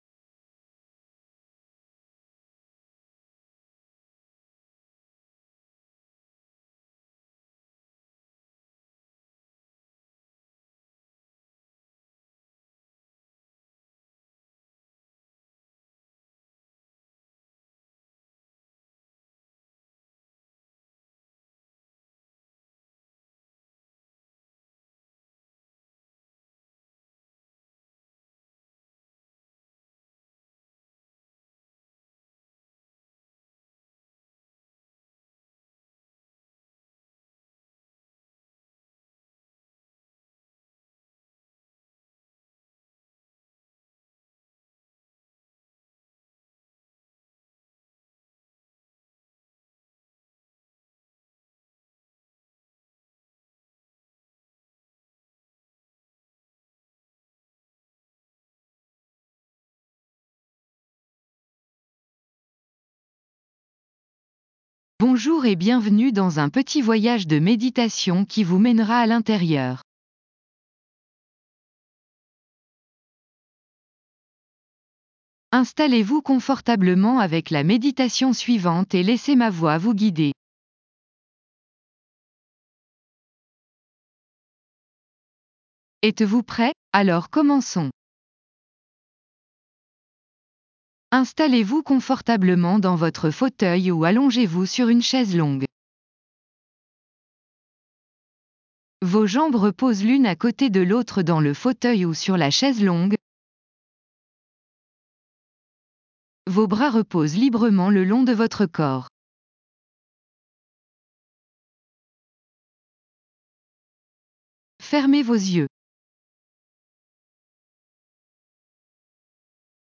Restez concentré sur ces passages pendant que vous laissez la musique vous submerger. Encore une fois, il n'y a pas d'affirmation.